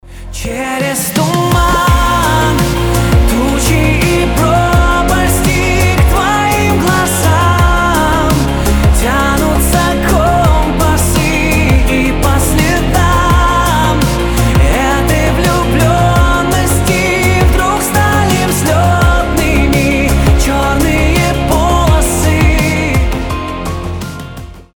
Красивый мужской голос
Поп